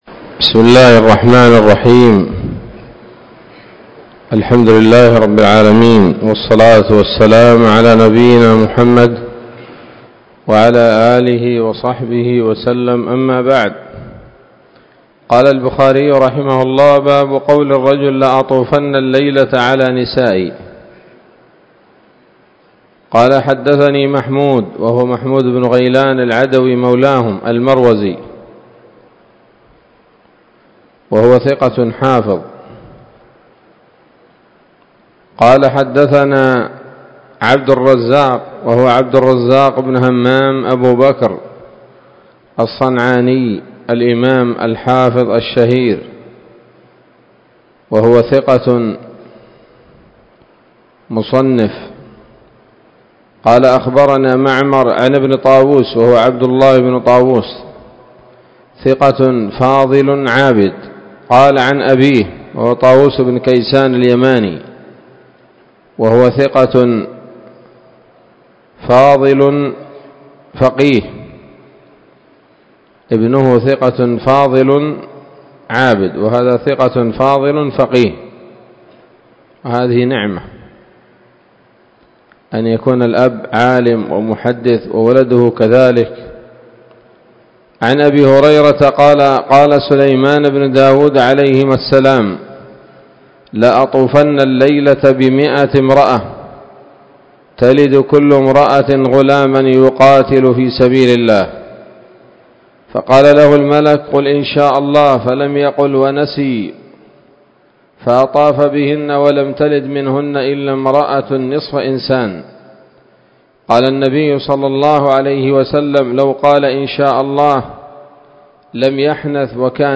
الدرس الثالث والتسعون من كتاب النكاح من صحيح الإمام البخاري